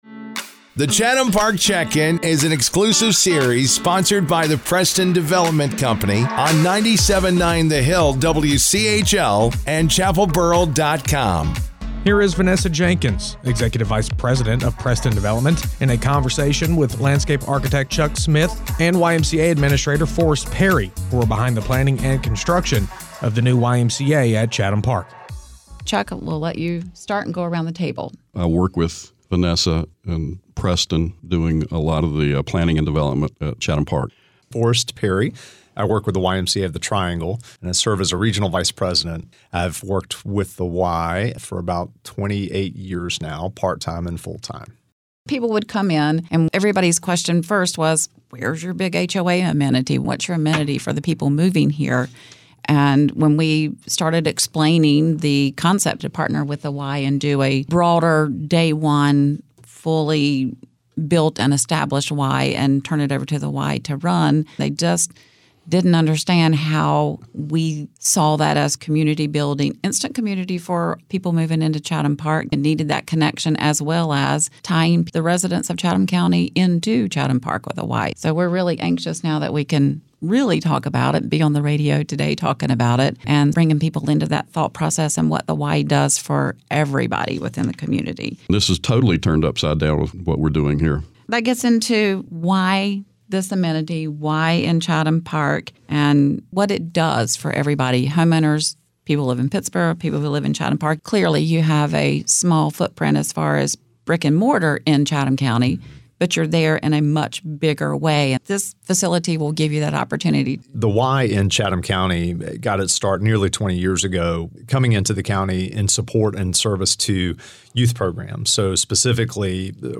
The Chatham Park Check-In continues with the next series of interviews